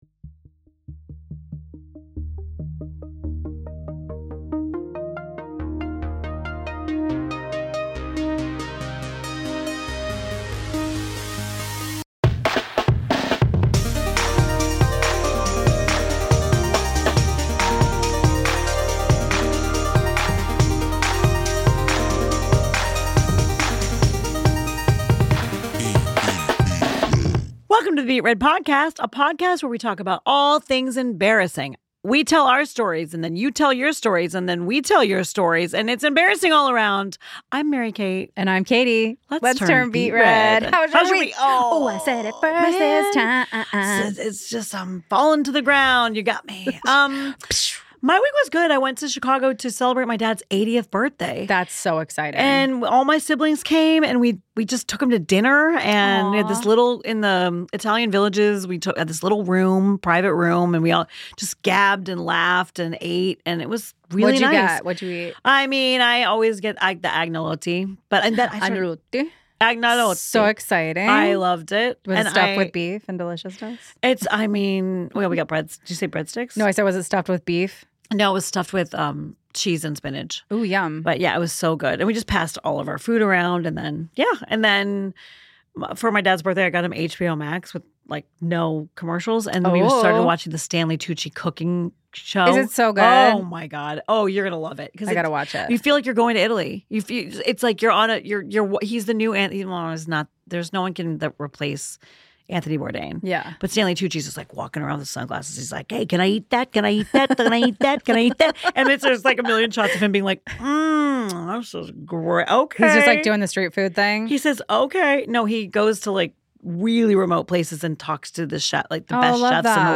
at PROJKT studios in Monterey Park, CA.